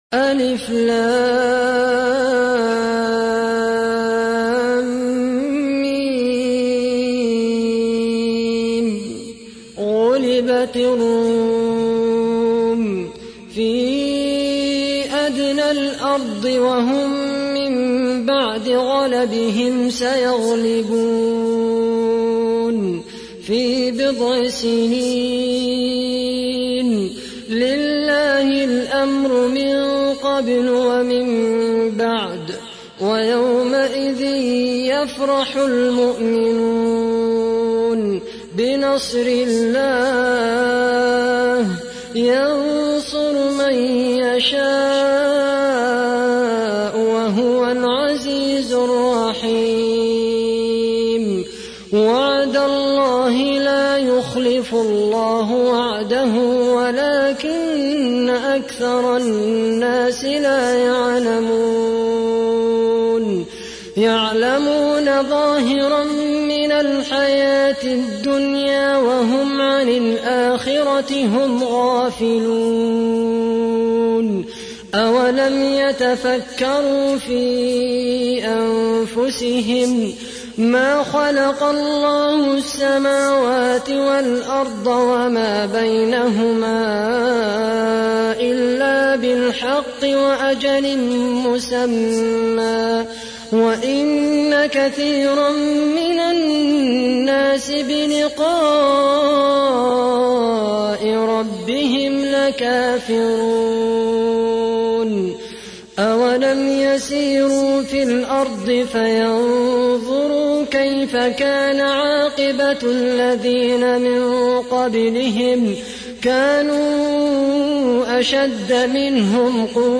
تحميل : 30. سورة الروم / القارئ خالد القحطاني / القرآن الكريم / موقع يا حسين